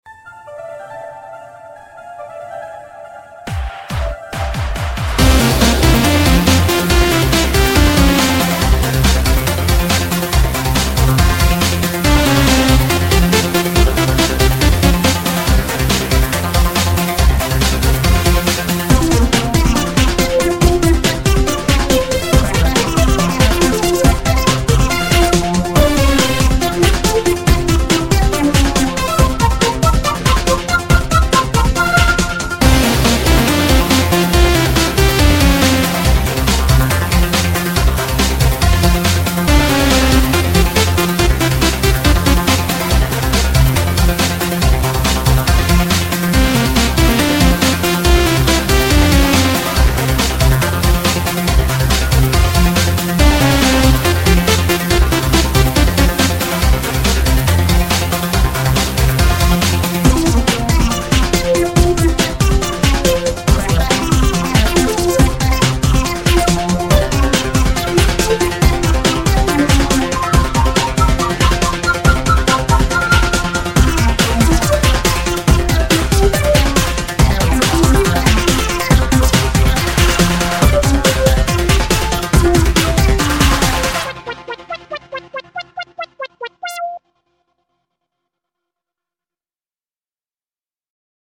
BPM140-140
Audio QualityMusic Cut